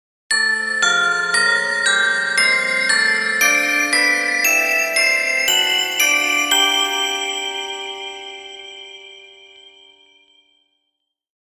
Ascending Bell Tones Sound Effect
Festive holiday bell melody with rising tones.
Ascending-bell-tones-sound-effect.mp3